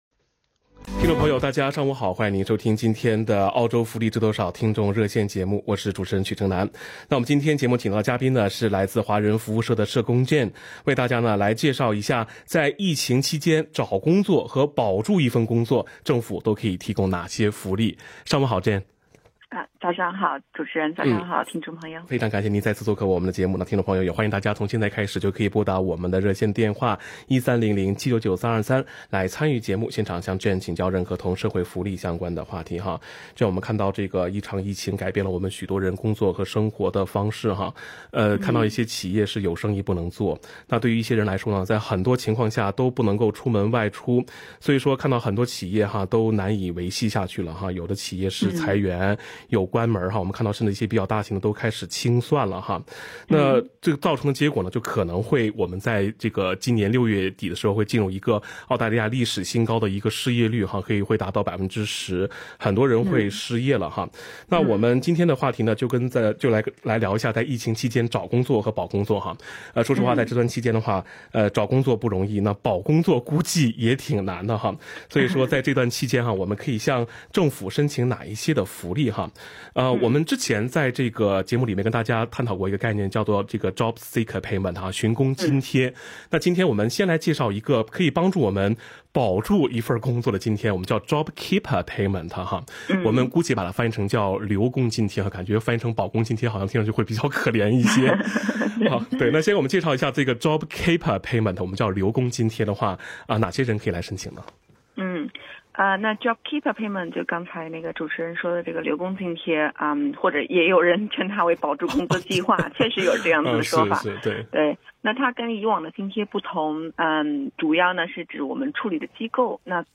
social_welfare_talkback_april_27_new.mp3